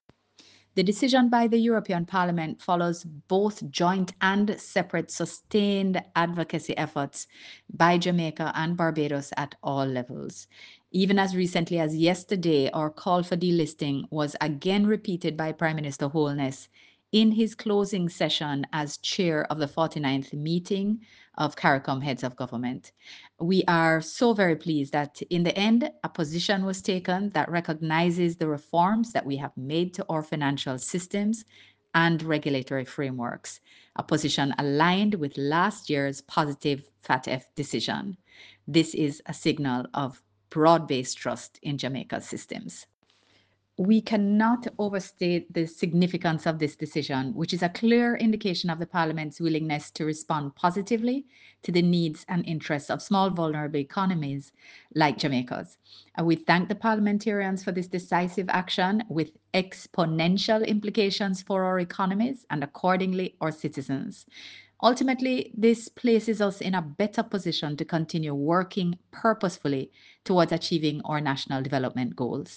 Foreign-Minister-Johnson-Smith-speaks-on-Delisting-AML-CTF-EU.mp3